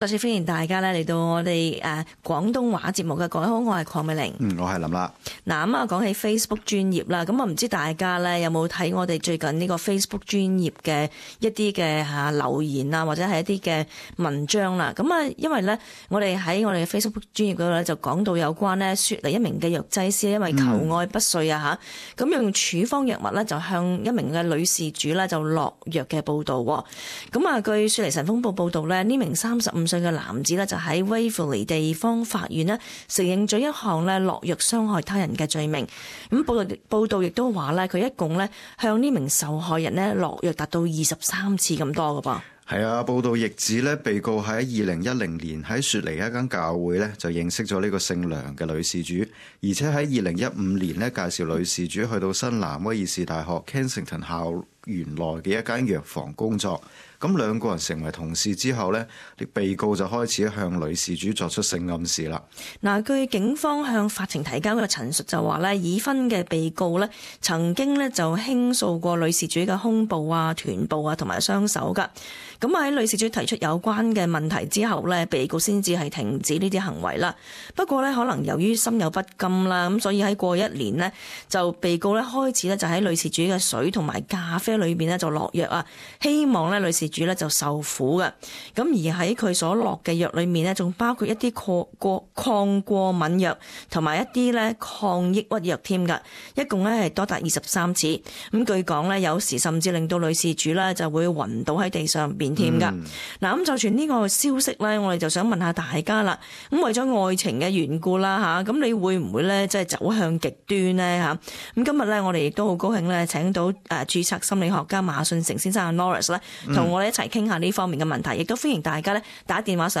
In this program we invited specialist to assess some risk factors for aggressive dating or some forms of male-against-female sexual aggression in dating situations.